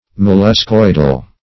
Molluscoidal \Mol`lus*coid"al\, a.